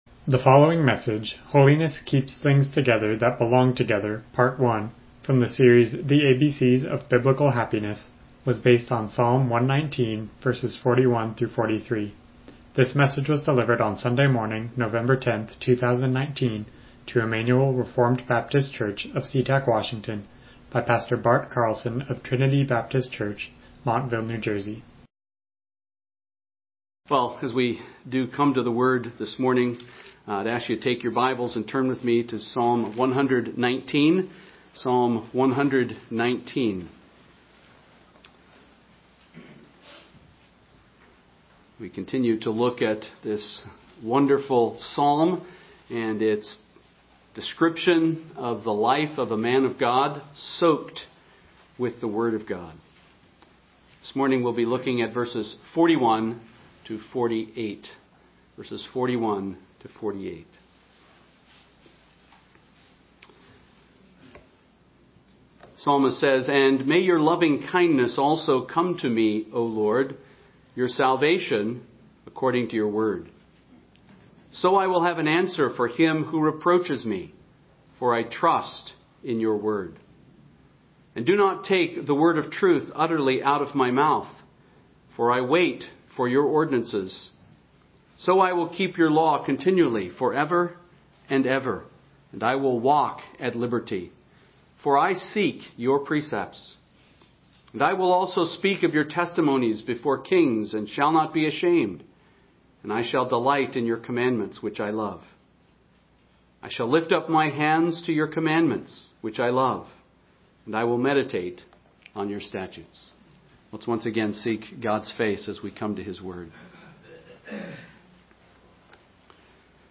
Passage: Psalm 119:41-43 Service Type: Morning Worship